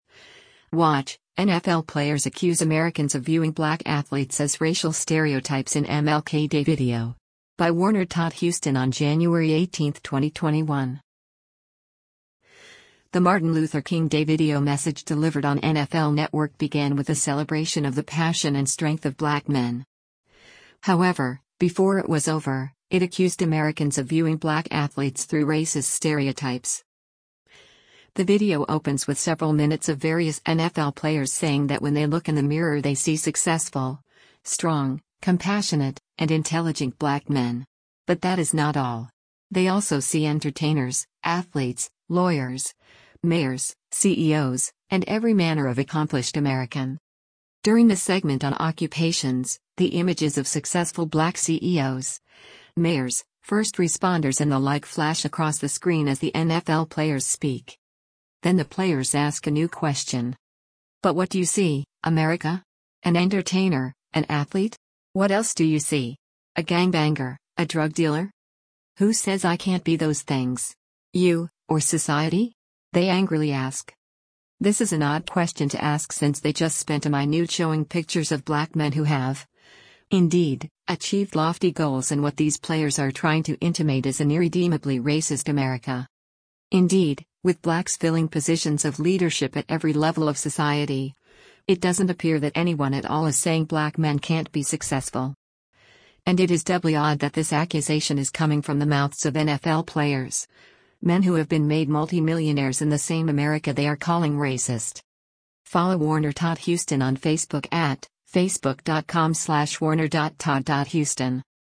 The Martin Luther King Day video message delivered on NFL Network began with a celebration of the passion and strength of black men.
The video opens with several minutes of various NFL players saying that when they “look in the mirror” they see successful, strong, compassionate, and intelligent black men.
“Who says I can’t be those things? You, or society?” they angrily ask.